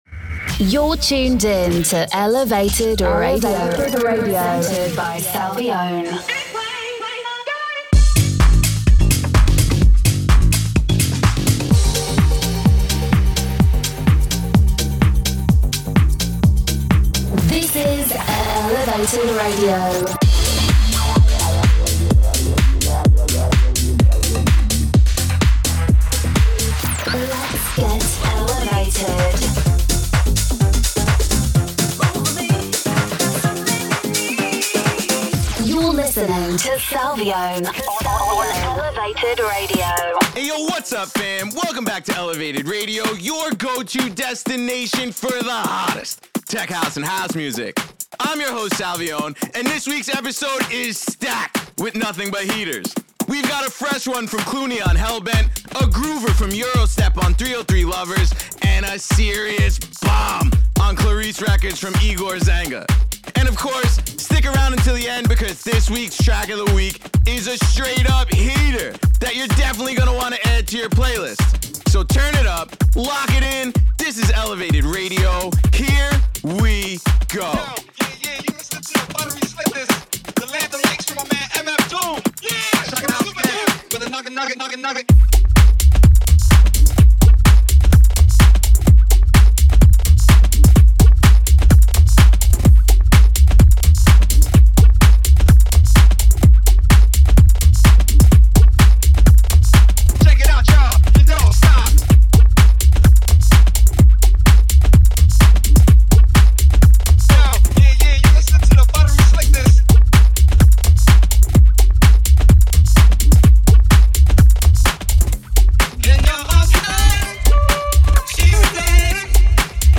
and established international house and tech house artists.